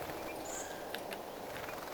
Tuli äänitys sen lentoonlähtöäänestä:
sinihohtorastaskoiras,
lentoonlähtöääni
videon_sinihohtorastaskoiraan_aani_lentoon_lahtiessa.mp3